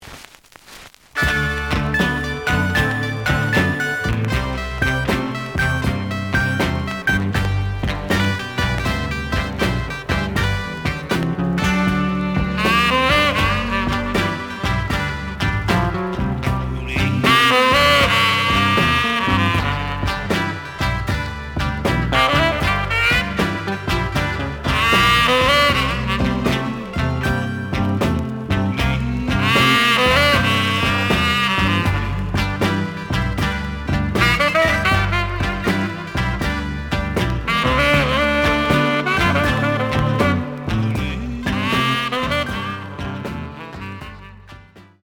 The audio sample is recorded from the actual item.
●Genre: Blues